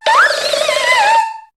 Cri de Brocélôme dans Pokémon HOME.